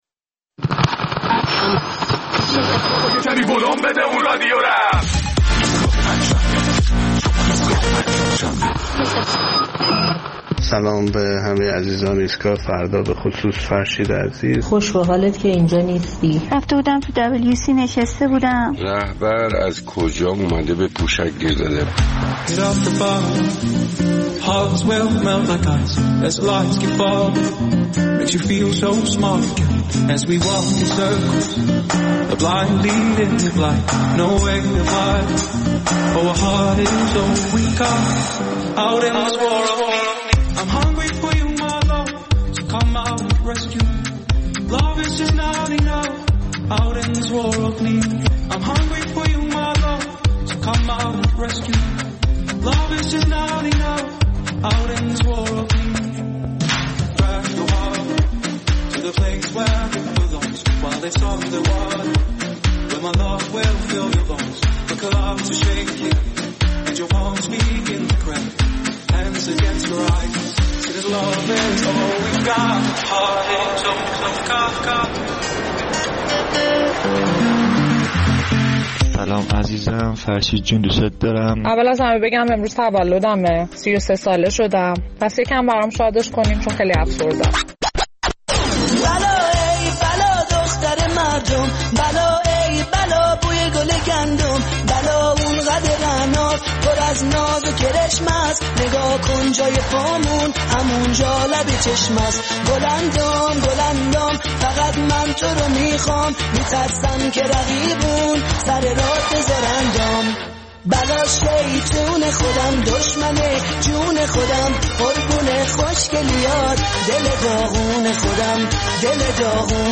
در این برنامه ادامه نظرات شنوندگان را در مورد صحبت‌های رهبر ایران درباره تاثیر خرابکاری دشمنان در کمبود پوشک در کشور می‌شنویم.